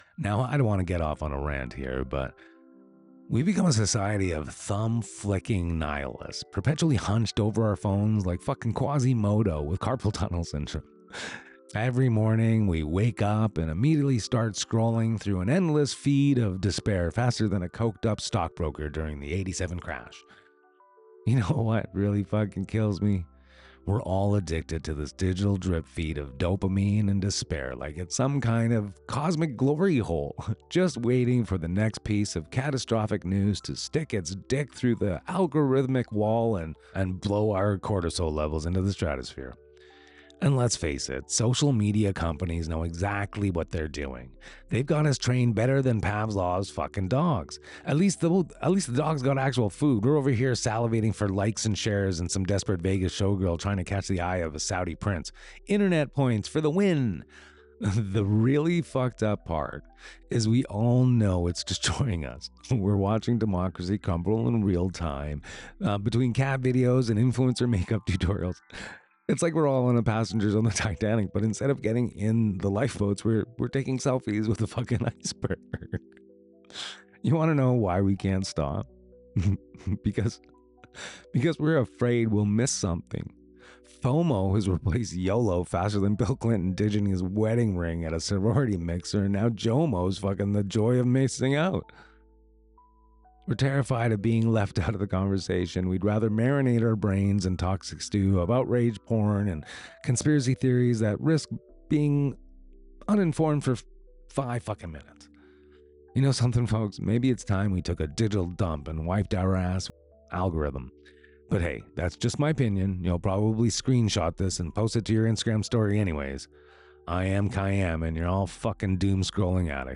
004-RANT.mp3